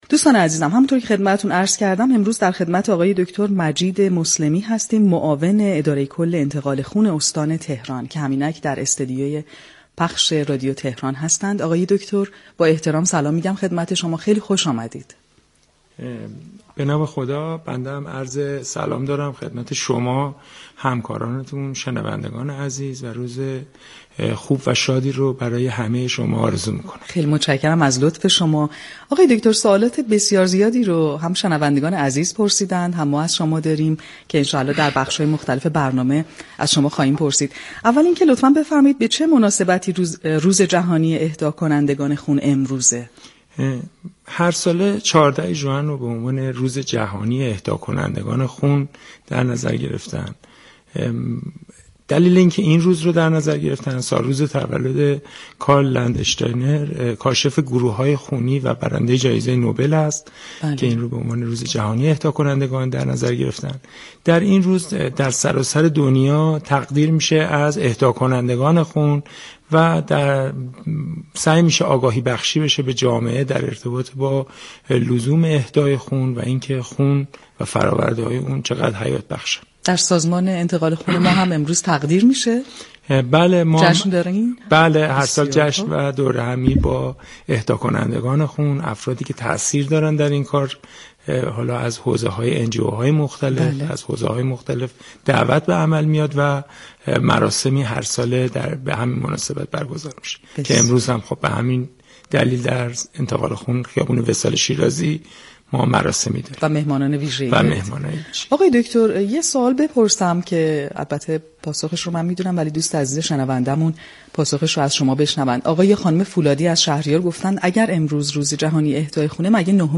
با حضور در استودیو پخش زنده رادیو تهران